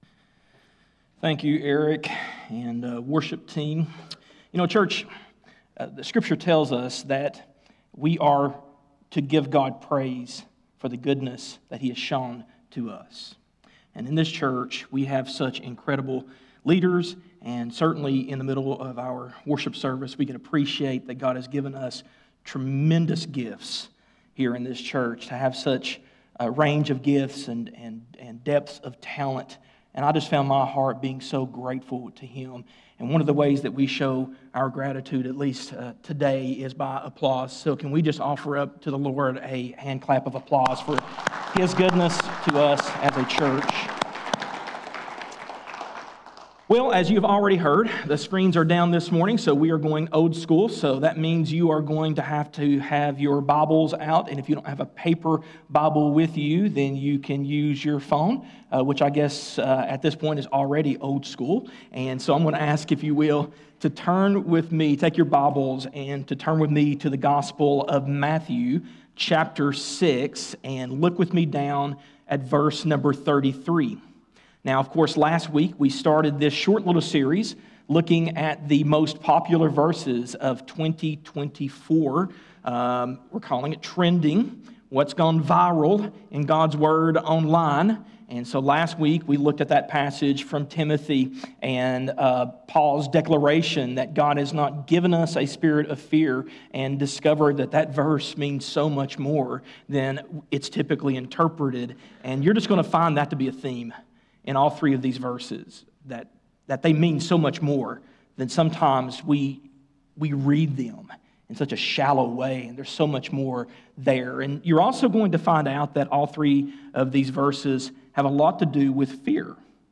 In today's sermon, we explored James 4:13-17 and the subtle sin of making plans without God.